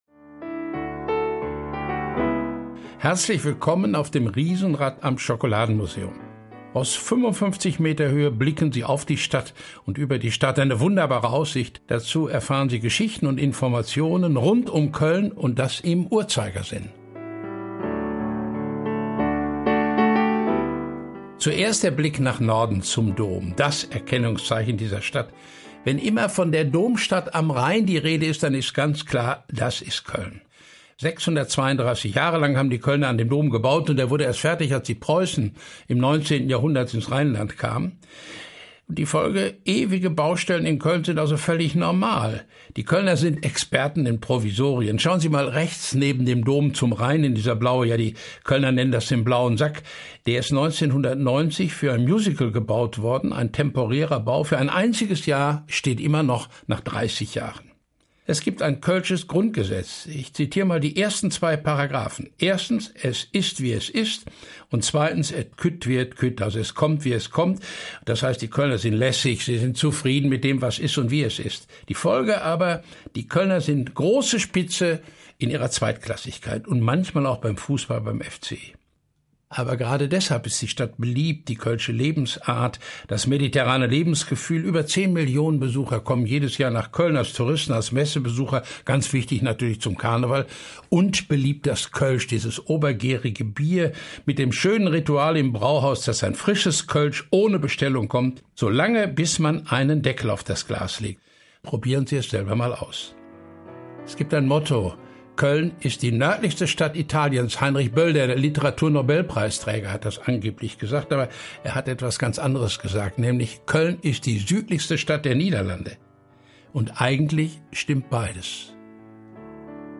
The audio guide for the "Ride on the Ferris wheel":
audioguide-2023.mp3